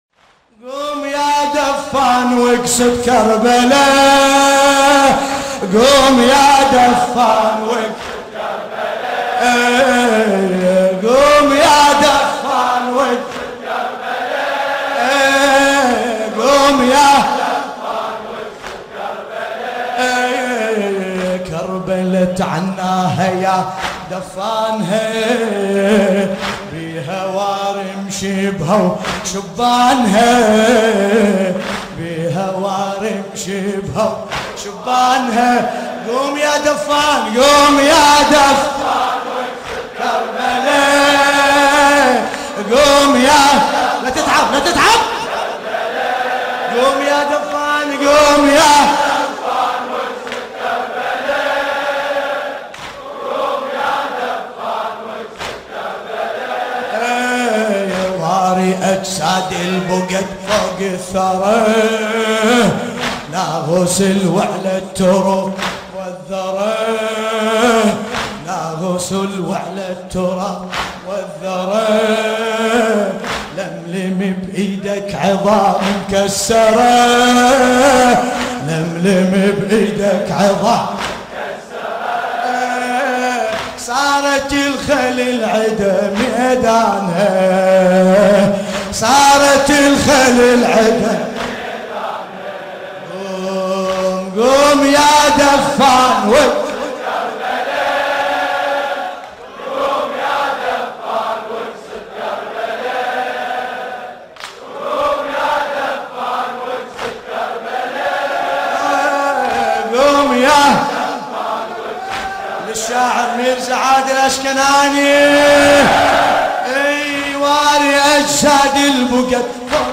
وأنشدها بصوته العذب